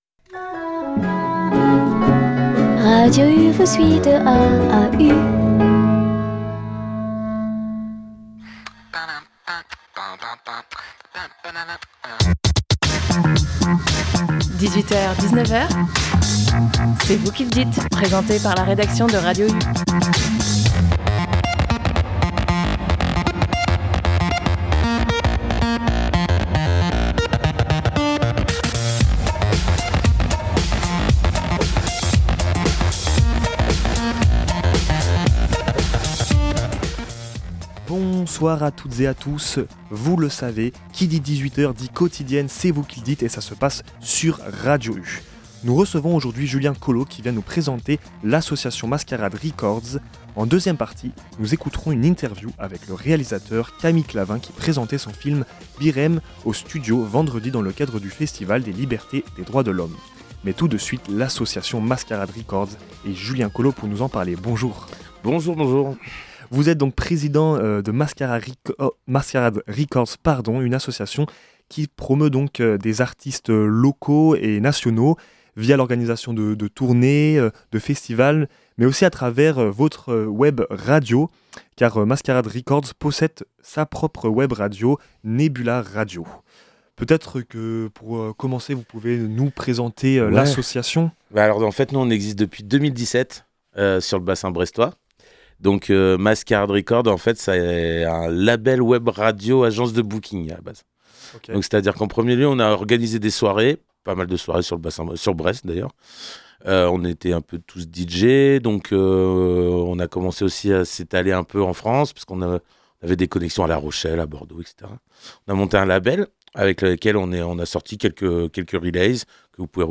Dans cette émission, nous recevions les organisateurs du Flow Festival, qui se tiendra ce samedi 15 février à 20h00 à la Carène.